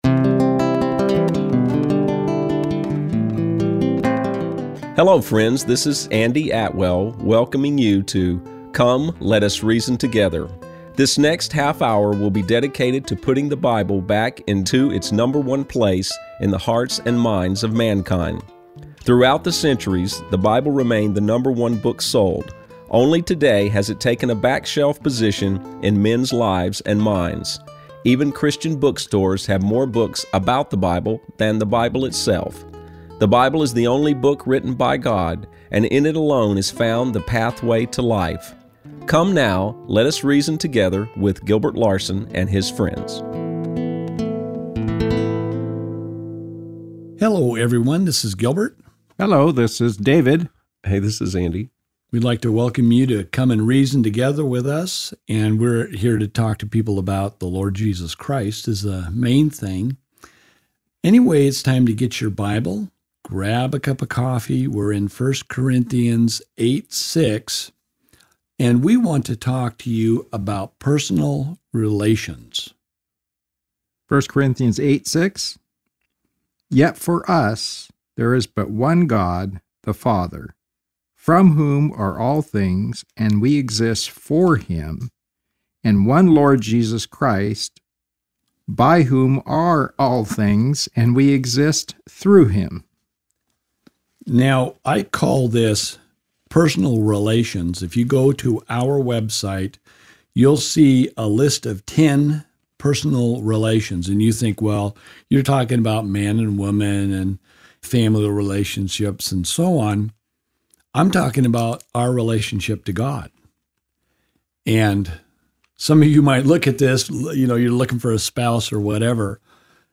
We have produced a radio program discussing the importance of forming and restoring relationships with God.